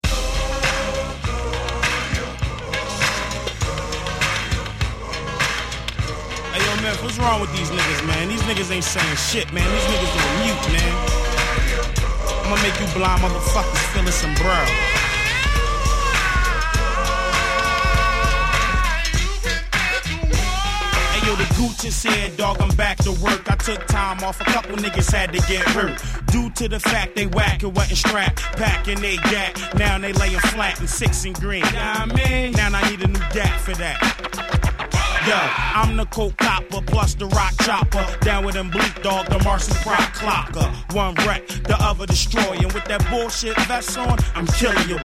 01' Big Hit Hip Hop !!